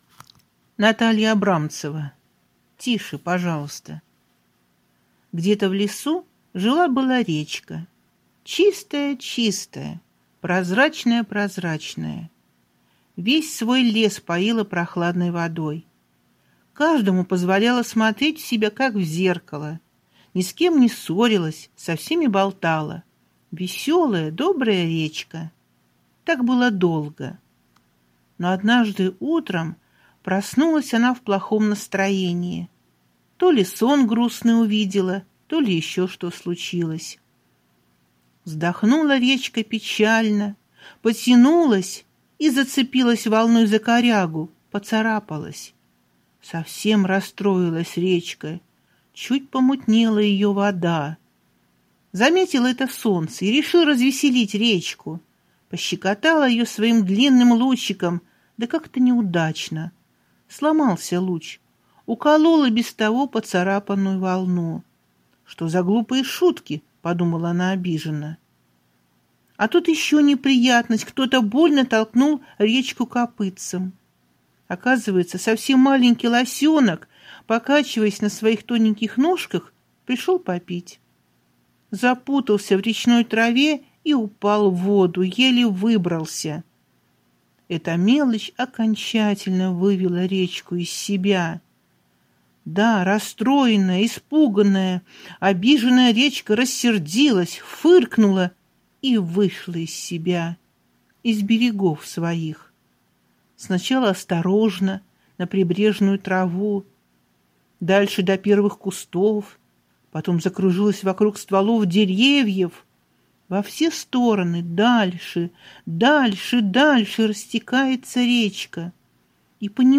Аудиосказка «Тише, пожалуйста»